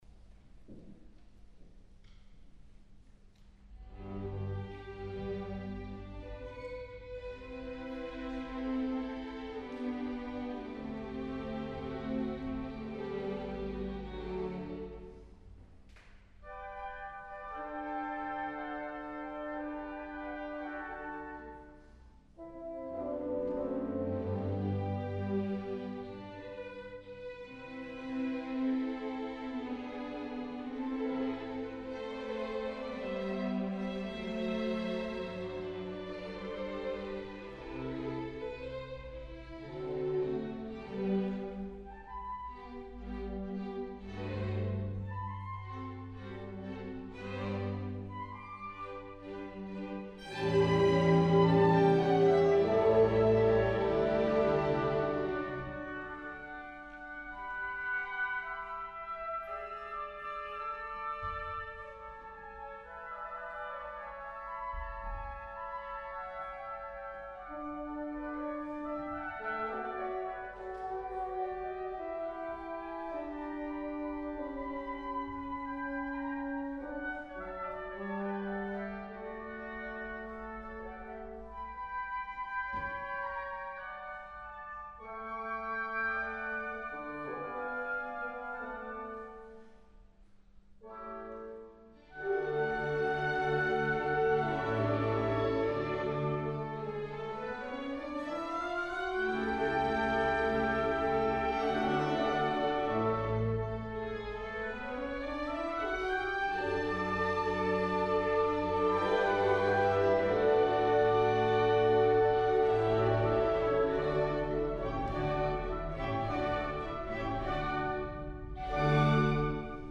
St. Laurentiuskirche Markranstädt
2. Adagio 3.